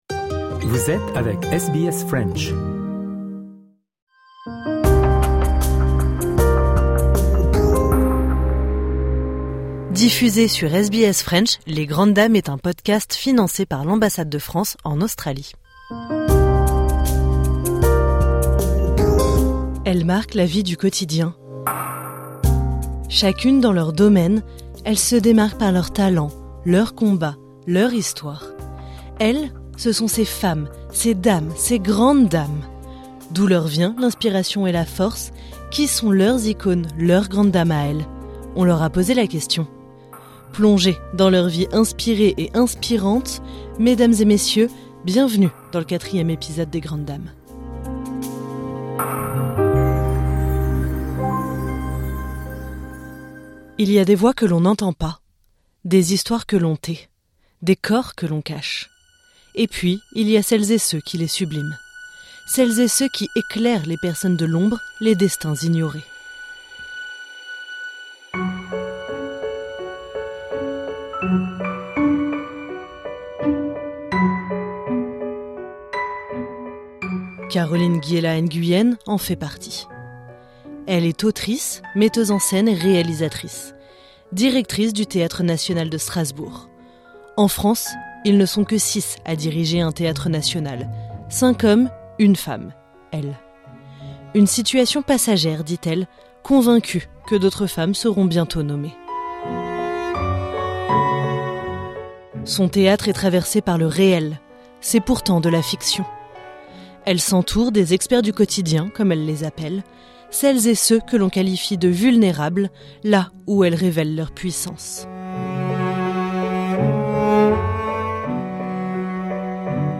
Cette interview a été enregistrée par téléphone, en janvier 2026.